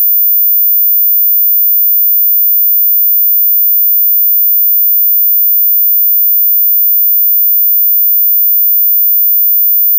Bild 1: 14kHz samt 15kHz sinus ihopsnickrat i datorn. Grafen visar en analys av en 10s lång wav-fil.. piiiiiip: